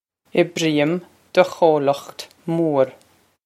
Pronunciation for how to say
Ib-reem duh co-lokht moor.
This is an approximate phonetic pronunciation of the phrase.